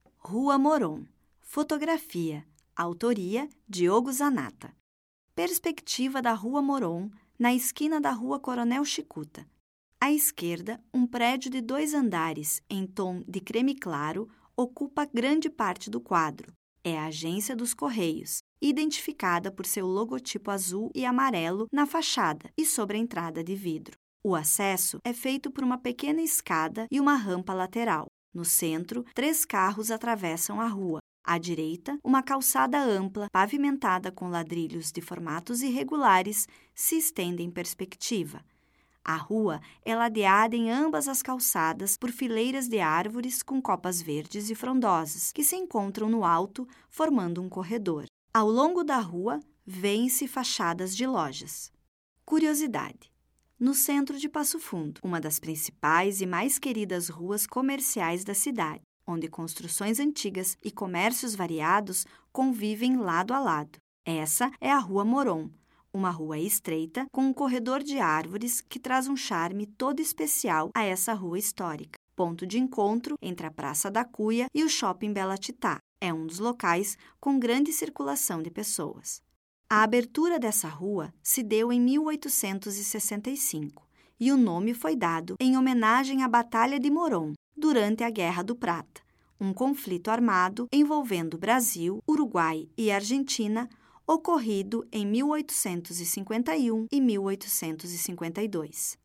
Audioguia
Audiodescrição